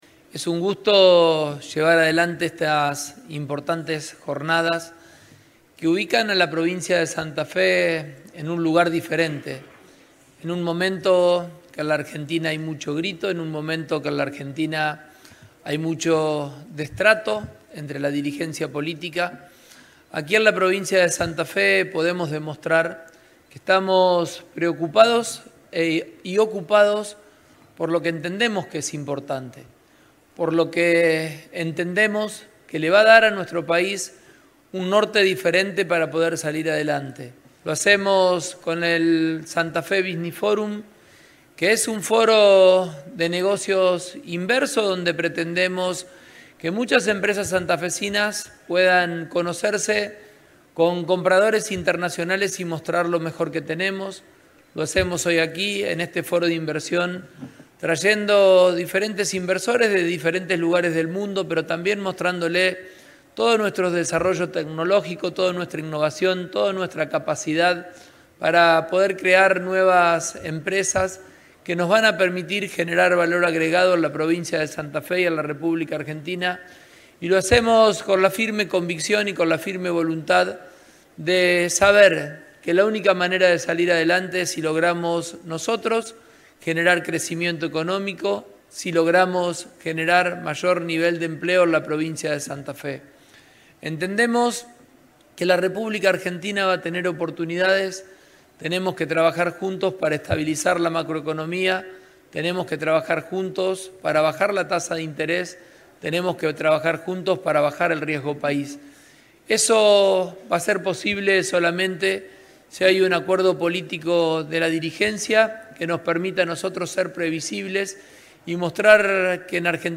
El gobernador inauguró el Foro de Inversiones del Santa Fe Business Forum 2025 en Rosario.